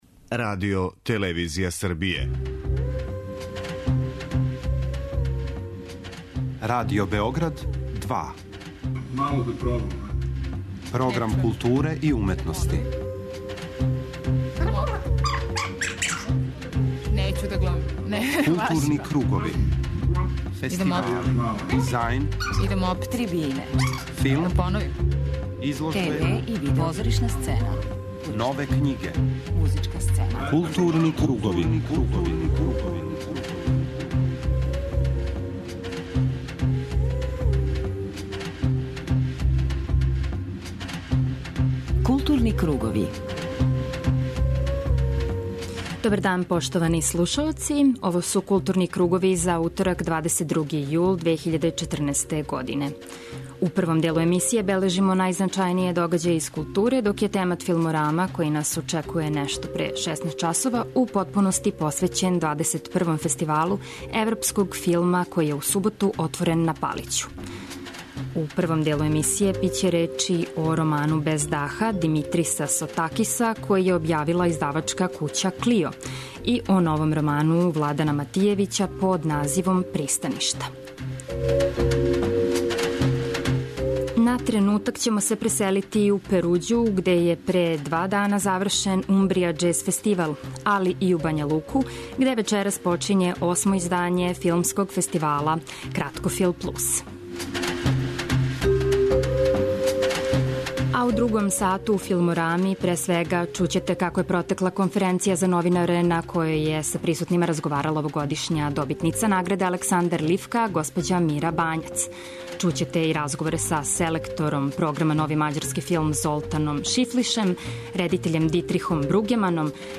Пре свега, чућете како је протекла конференција за новинаре на којој је са присутнима разговарала овогодишња добитница награде "Александар Лифка", госпођа Мира Бањац, глумица која је филмску каријеру почела 1968. године улогом у музичкој комедији Соје Јовановић Пусти снови.